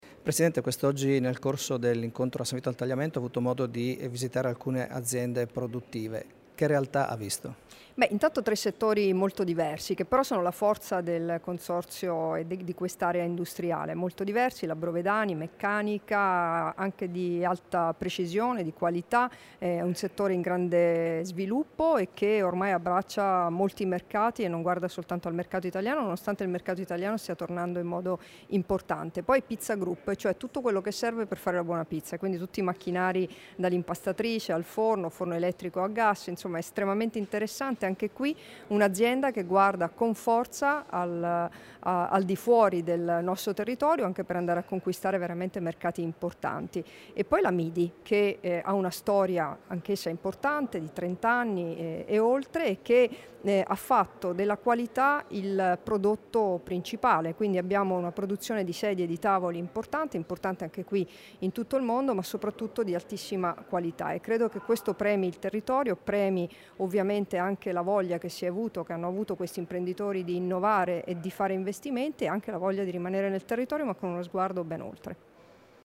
Dichiarazioni di Debora Serracchiani (Formato MP3) [1224KB]
in occasione della visita presso alcune aziende del sanvitese, rilasciate a San Vito al Tagliamento il 31 luglio 2017